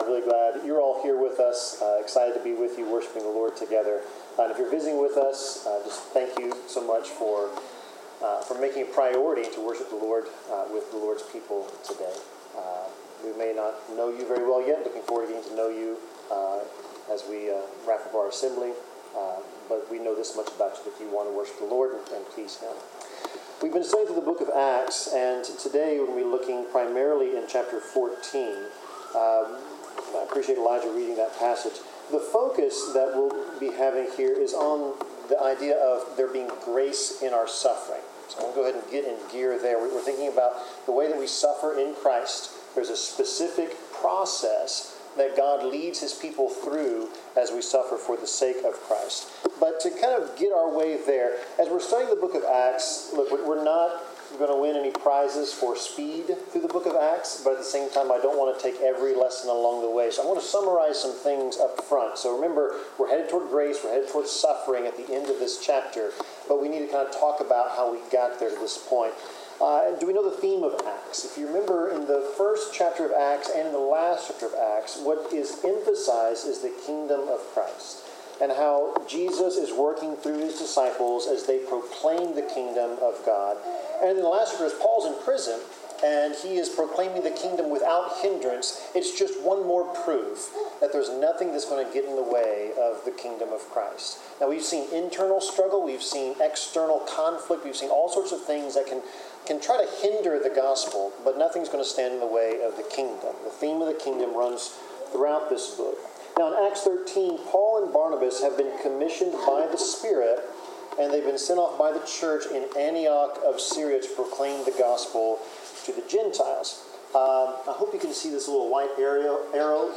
Bible class: Psalms 30-31
Service Type: Bible Class